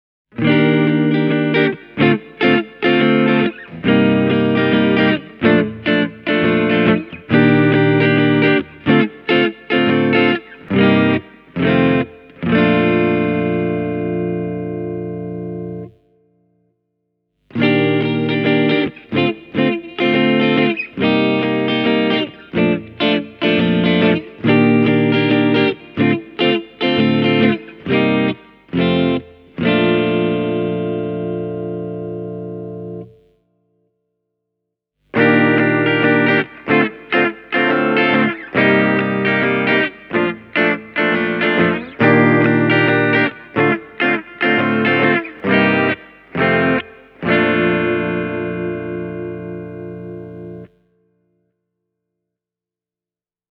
What is surprising, though, is that the LS-160 has the most ”vintage-accurate” tone of this trio, when plugged into an amplifier, even though the pickups are exactly the same as in the LS-130F. The neck pickup is dipped deep in tasty cream, while the bridge pickup is more dynamic and a bit brighter than many would expect from an LP Standard. The end result is a very versatile clean tone: